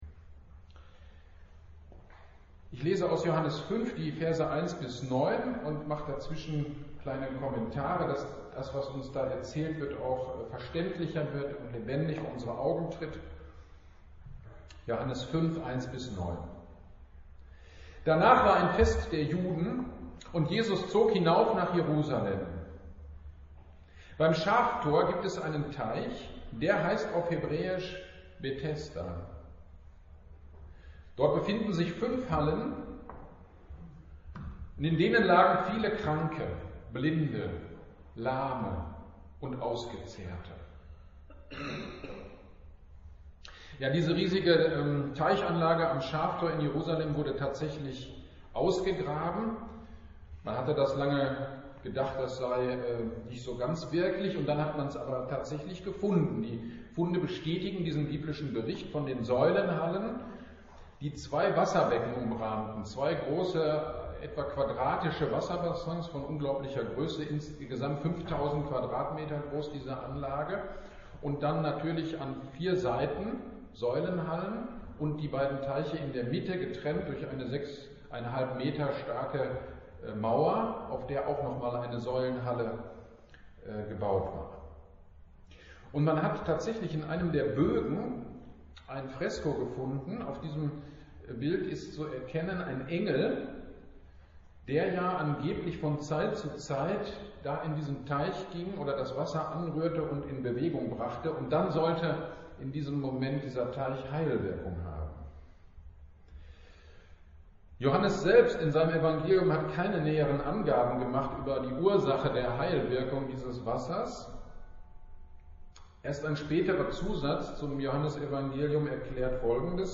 GD am 14.08.22 Predigt zu Johannes 5.1-9